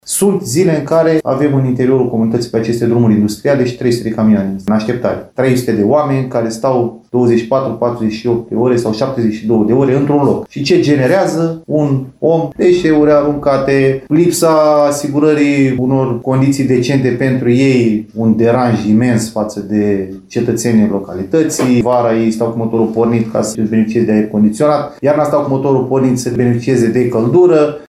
În unele zile, ajung să staționeze și câte trei sute de autocamioane, ceea ce creează mari probleme în comunitate, spune primarul din Isaccea, Ștefan Niculae: